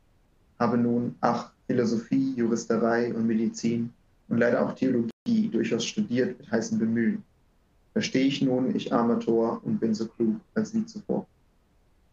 Die Aufnahmen wurden in verschiedenen Abständen aufgenommen.
Ein Hinweis: Die Aufnahmen wurden in einem akustisch gut ausgestatteten Videokonferenzraum aufgenommen.
Abstand 200 cm - Mikrofon vom Sprecher abgewandt
200cm abgewandt in Pexip © vcc